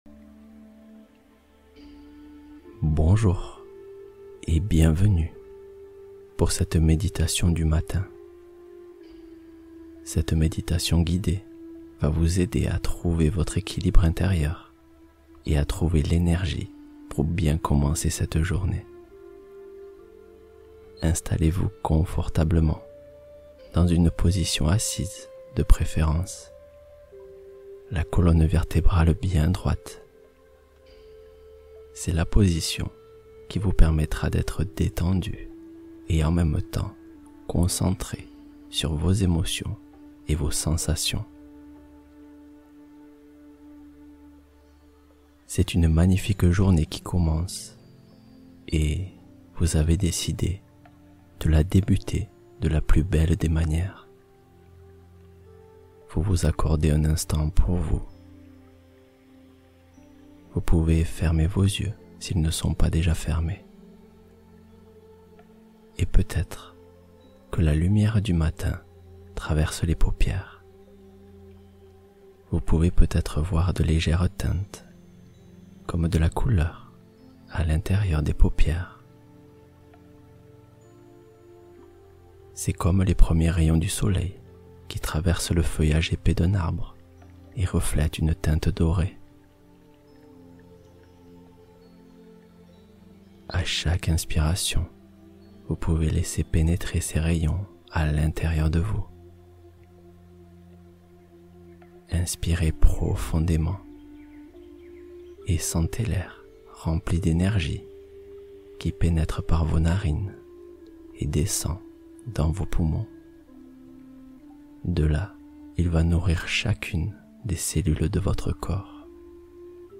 Démarrez Votre Journée Avec Confiance Et Énergie ILLIMITÉES | Méditation Matinale Puissante